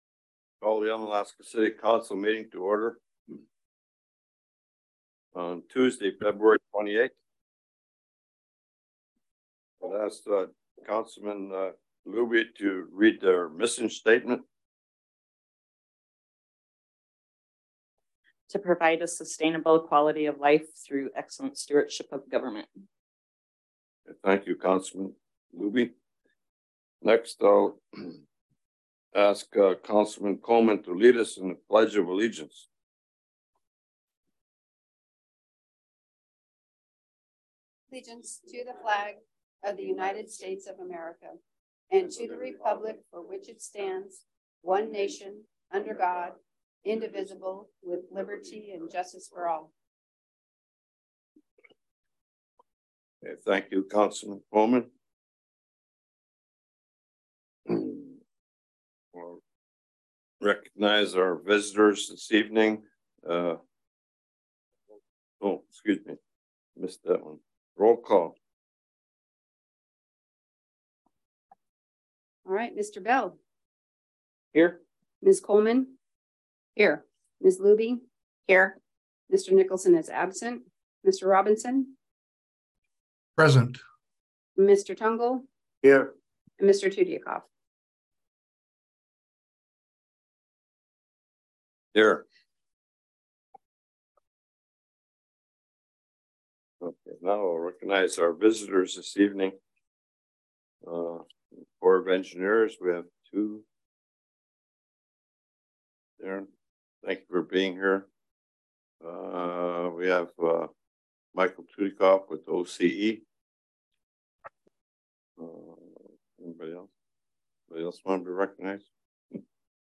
City Council Meeting - February 28, 2023 | City of Unalaska - International Port of Dutch Harbor